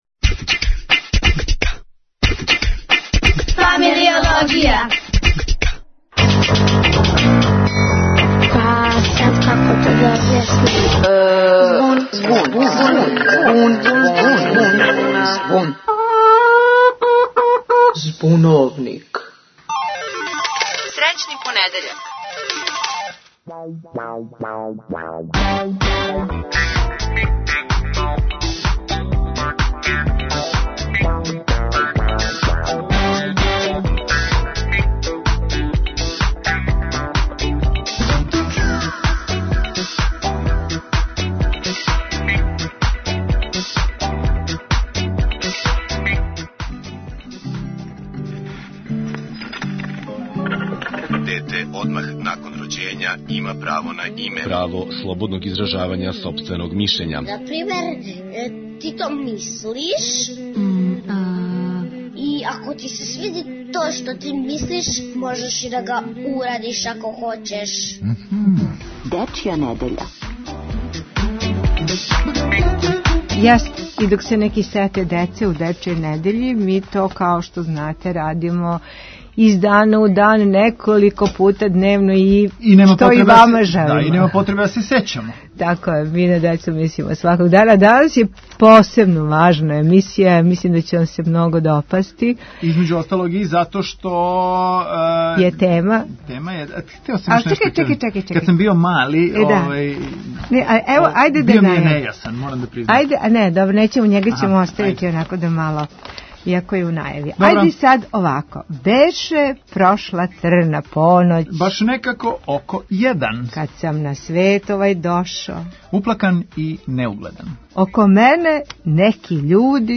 Говоре деца, млади и Момчило Бајагић.